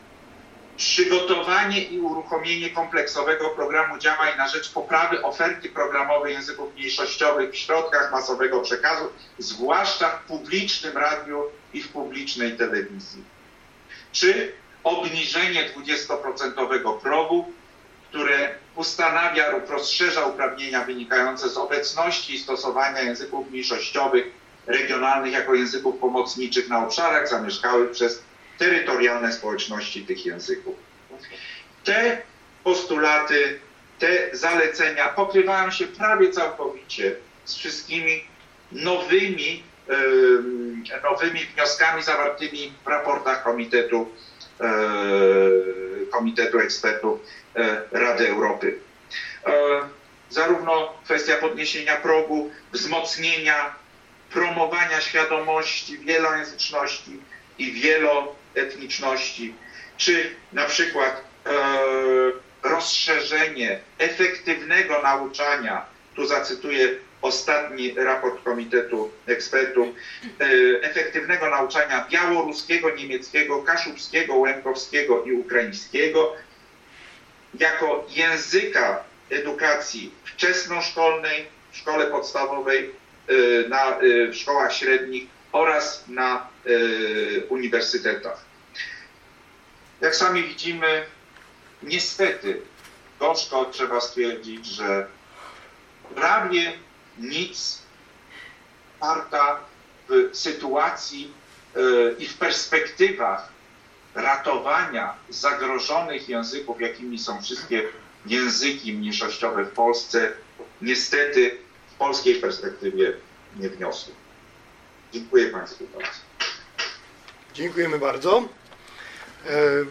Referat